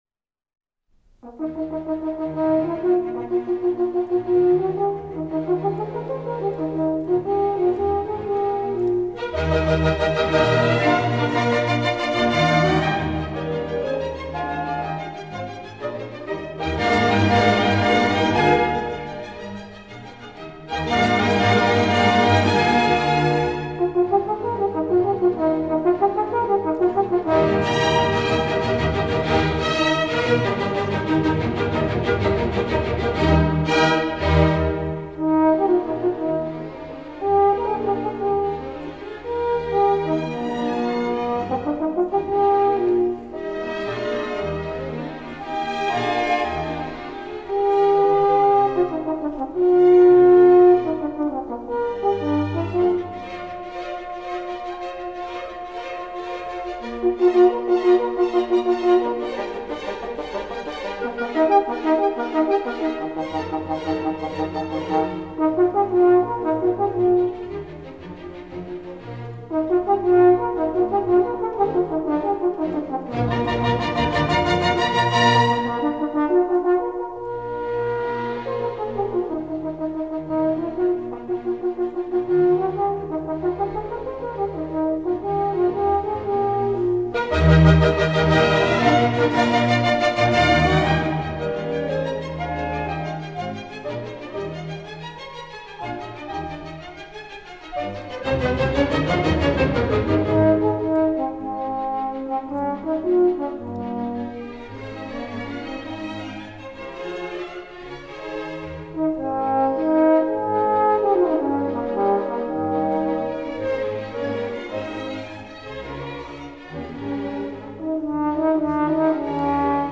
F08-Horn Concerto No. 3 in E flat maj | Miles Christi